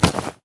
foley_object_grab_pickup_rough_02.wav